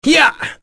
Roi-Vox_Attack1.wav